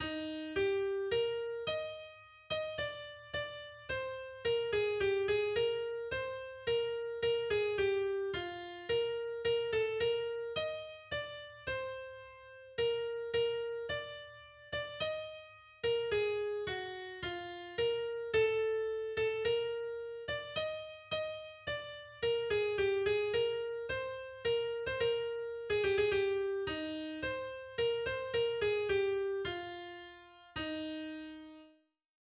Das Abendlied